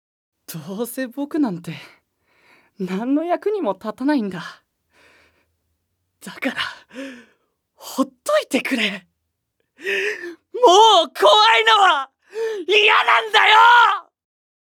●ボイスサンプル３